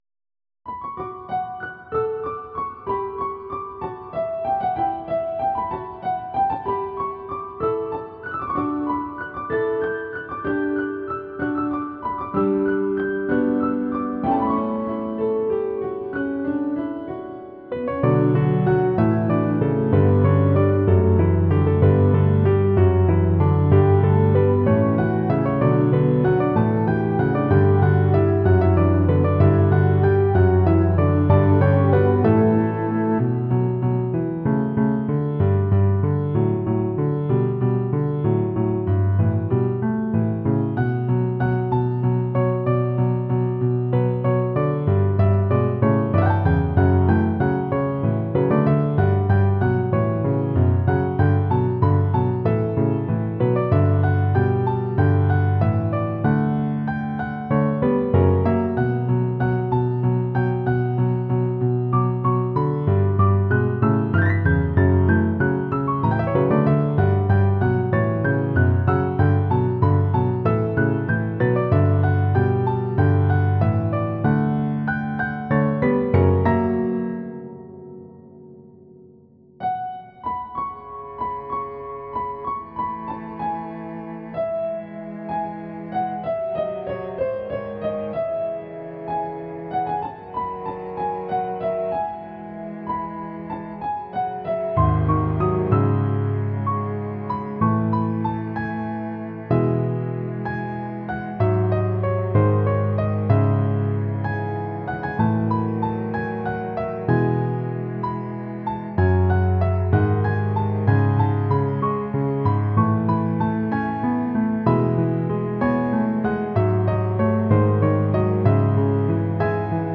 Ezen okok hatására kezdtem el saját zongoradallamokat írni, amikből ide csatolok egy párat